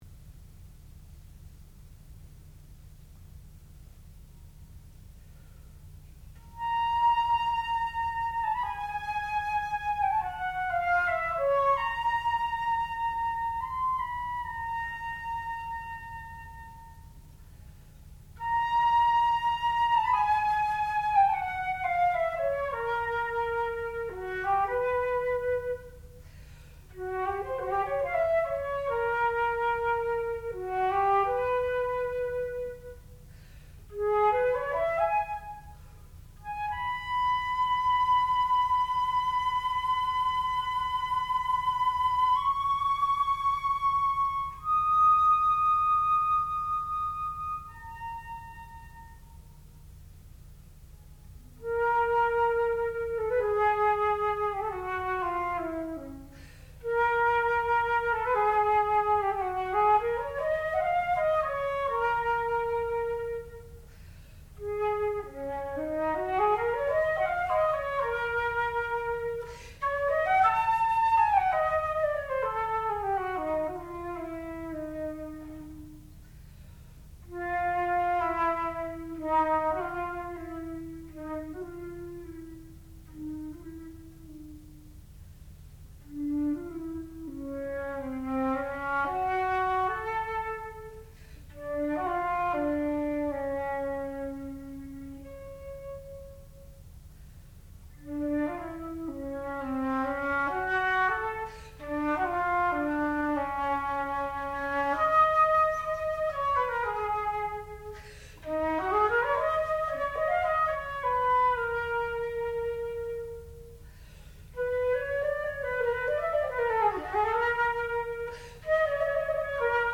Syrinx : for unaccompanied flute
sound recording-musical
classical music
Advanced Recital